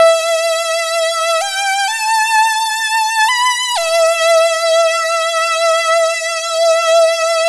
Retro Lead.wav